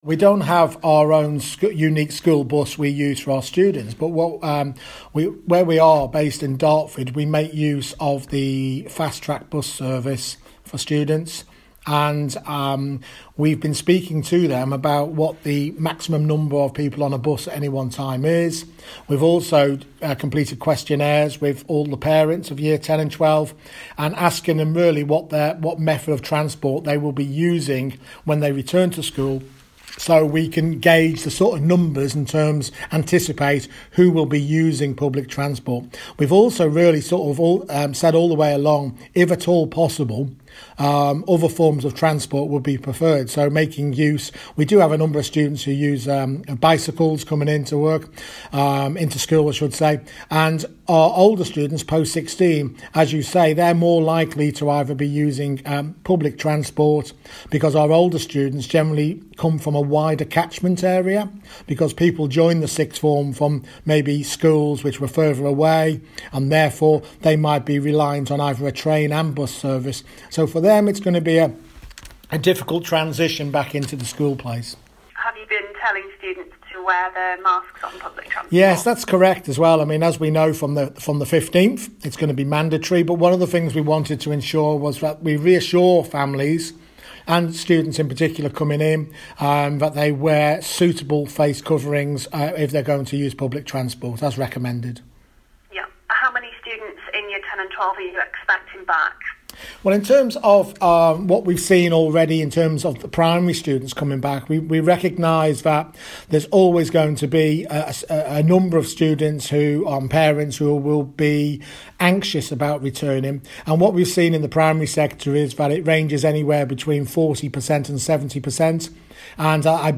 A Kent headteacher's told km-fm he expects it to be a difficult transition back to class for secondary school pupils who rely on public transport...